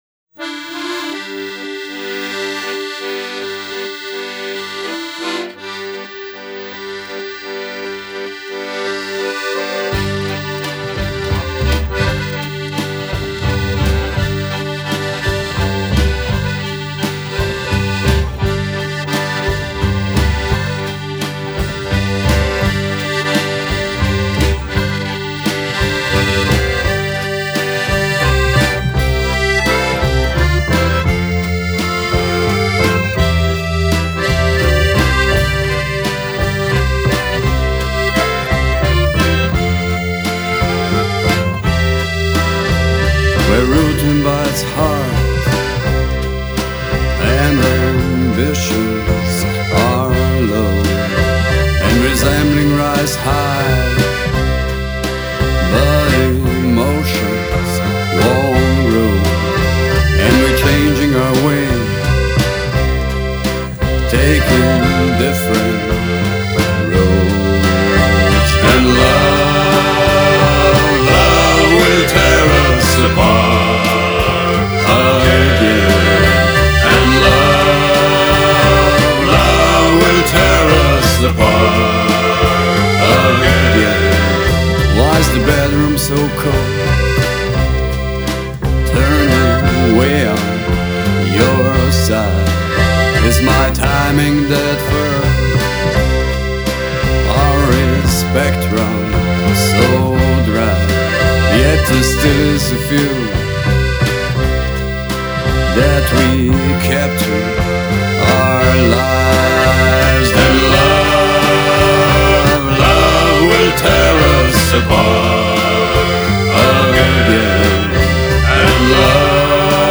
Harmonika in blues.